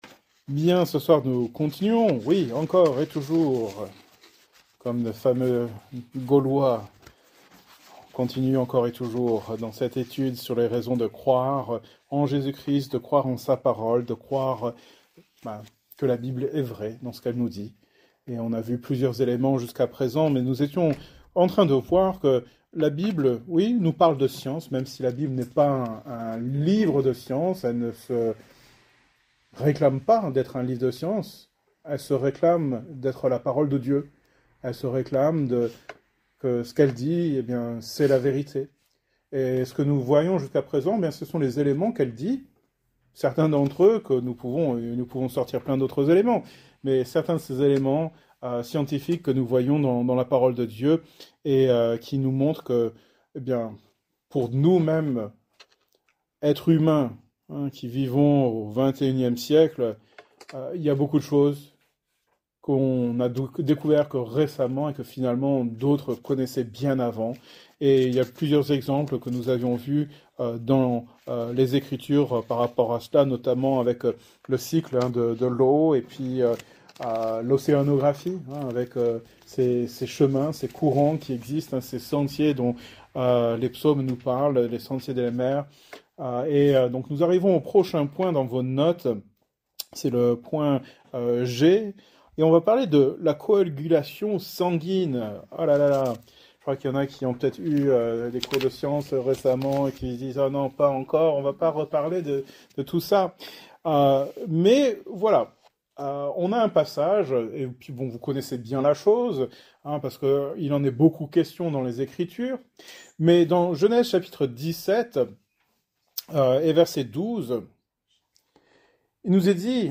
Thème: Apologétique , Foi Genre: Etude Biblique